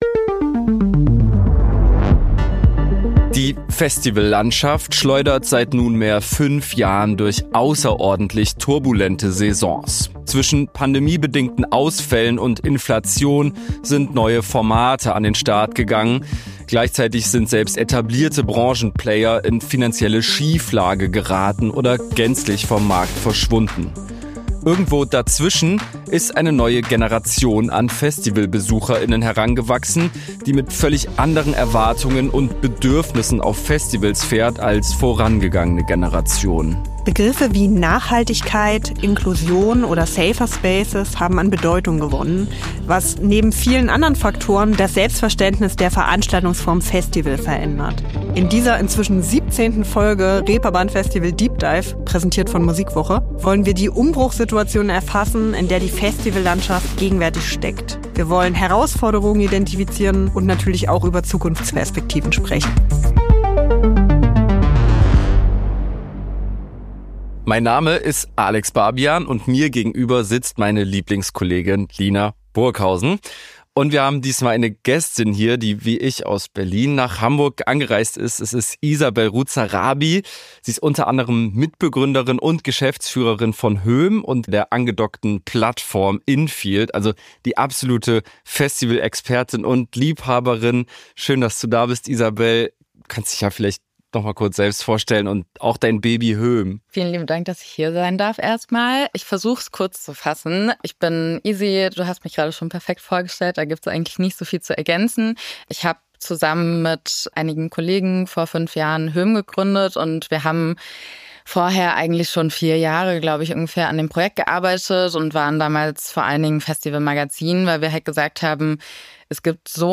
Studio: German Wahnsinn